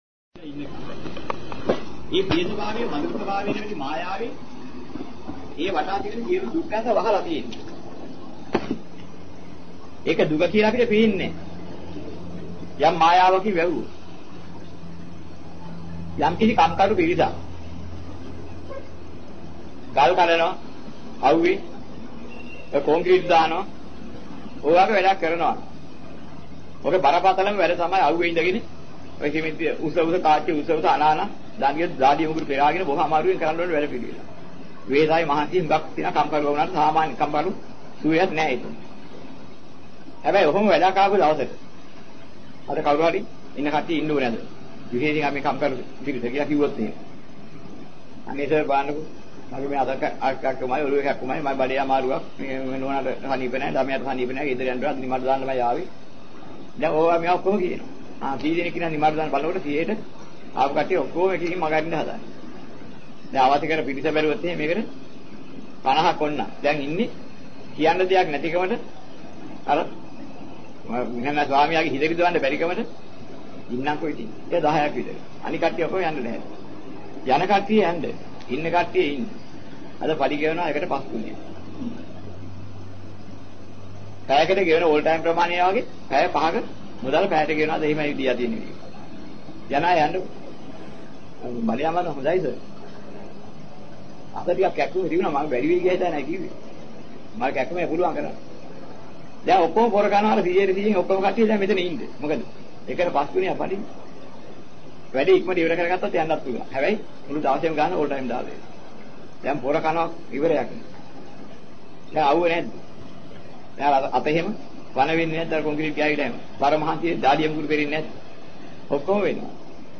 හත් දවසේ දානය.
මෙම දේශනාවේ සඳහන් වන ධර්ම කරුණු: